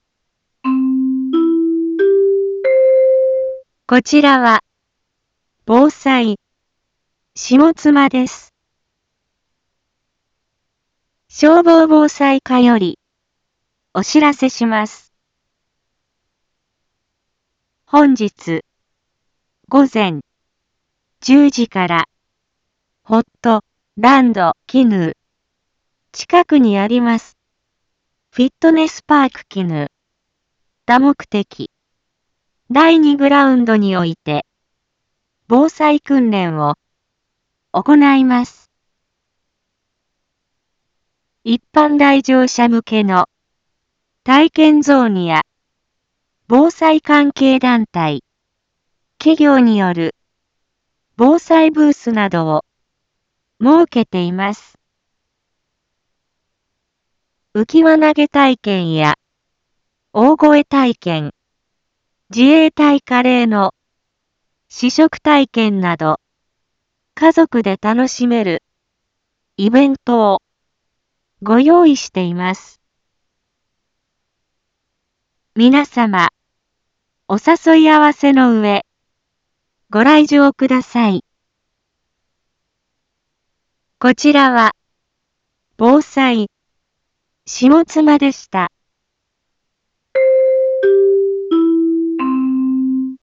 一般放送情報
Back Home 一般放送情報 音声放送 再生 一般放送情報 登録日時：2023-11-26 08:01:36 タイトル：防災訓練実施について インフォメーション：こちらは、防災、下妻です。